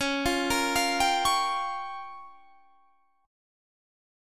Listen to C#6b5 strummed